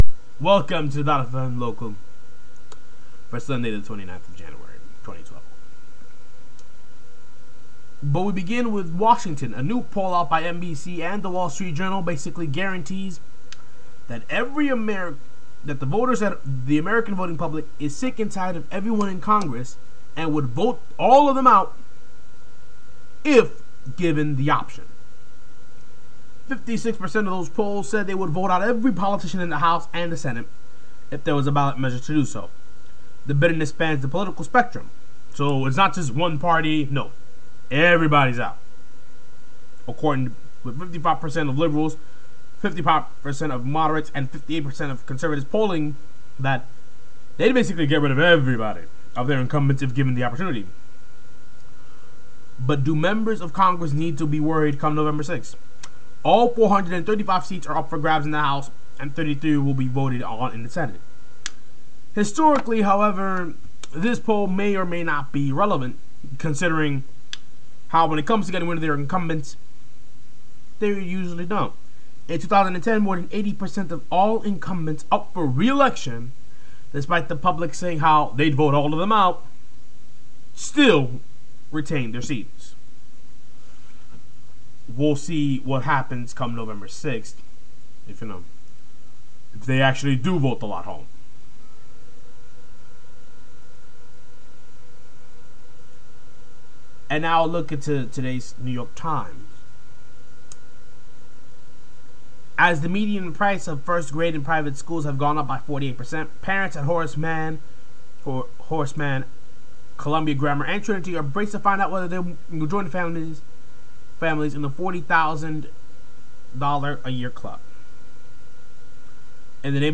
A look at the Sunday New York Times with a short weather summary and news bulletin